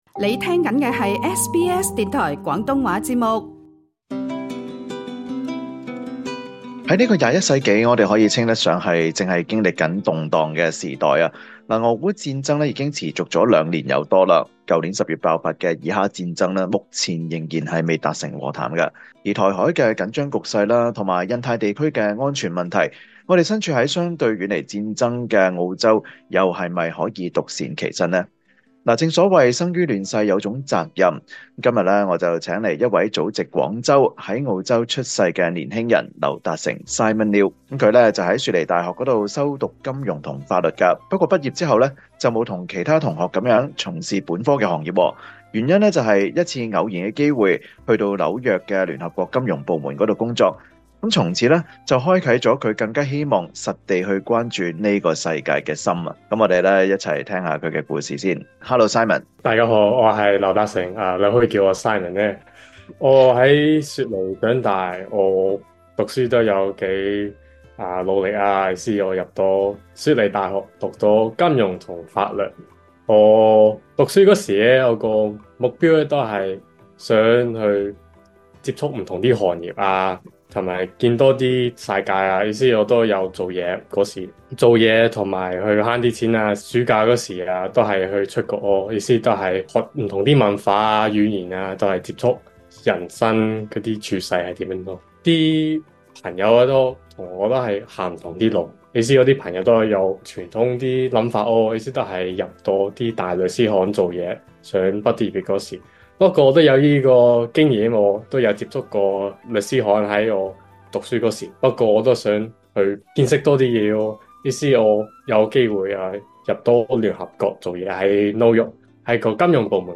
【星期日專訪】